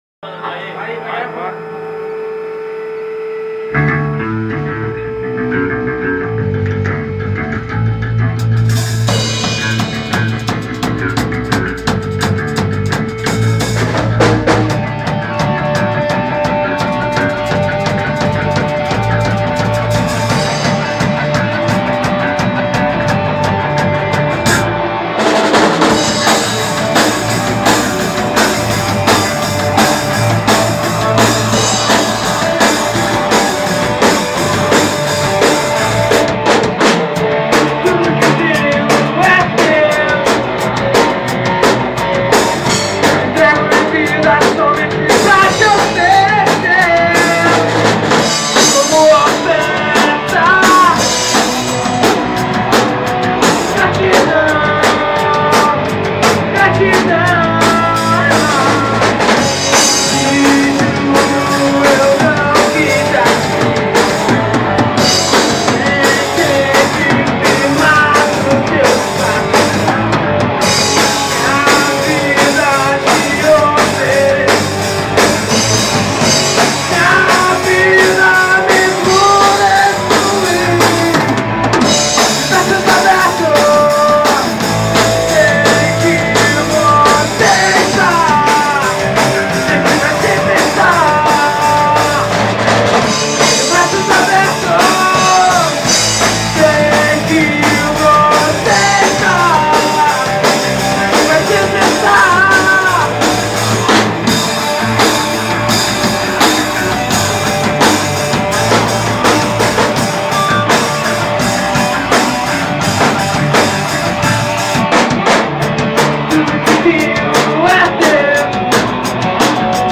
guitar e vocal